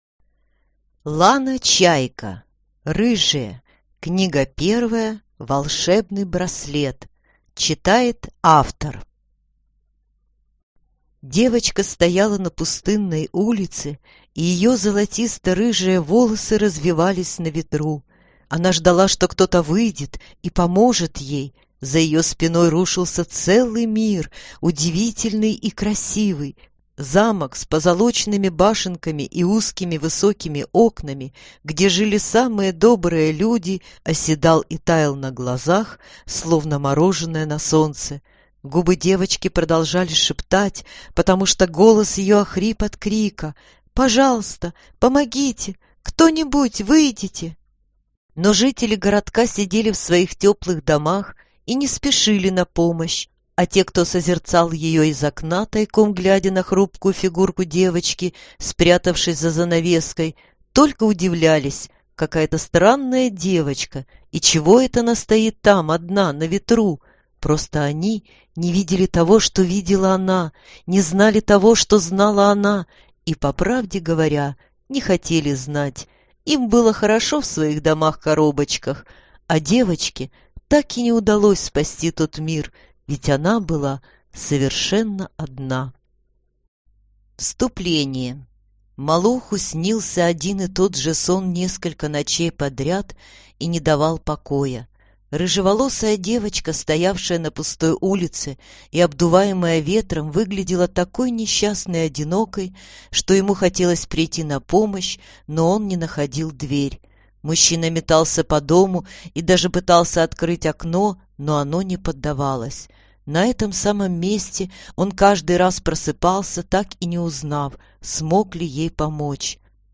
Аудиокнига Рыжие. Волшебный браслет | Библиотека аудиокниг